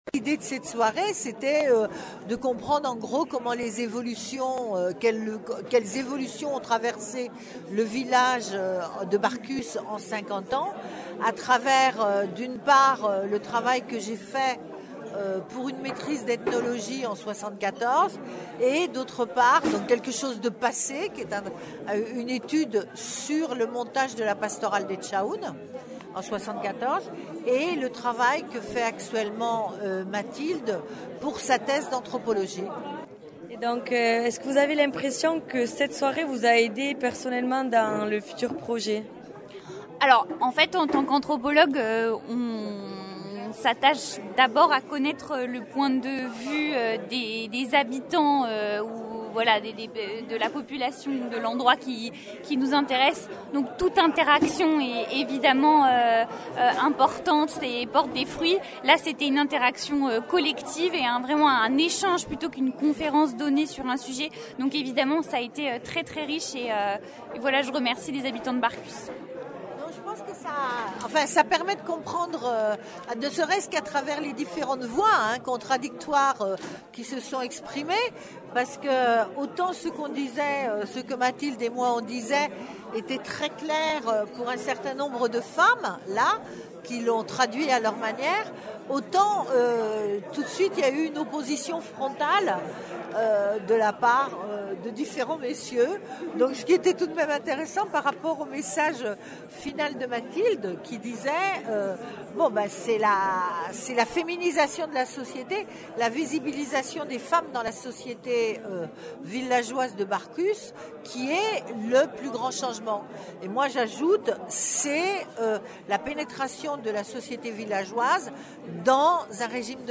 Eztabada izan da barkoxtar eta antrhopologueren artean.